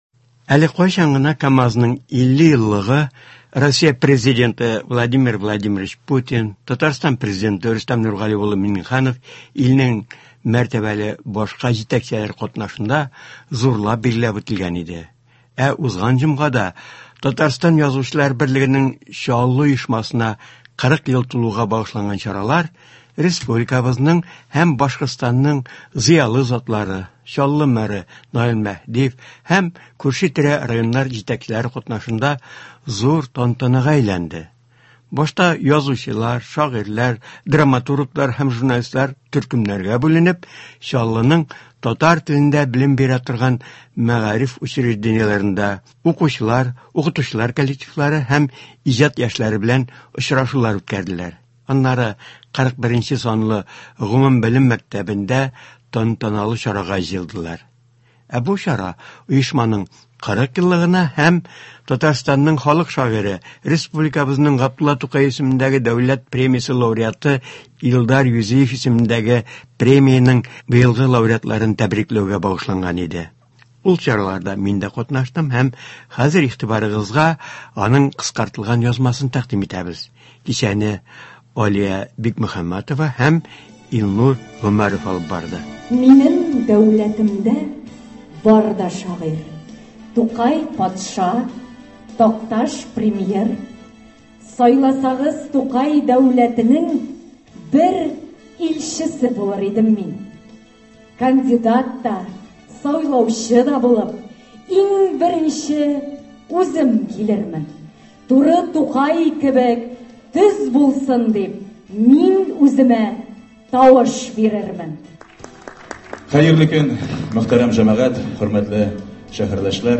Тыңлаучылар игътибарына юбилей тантанасыннан әзерләнгән репортаж тәкъдим ителә.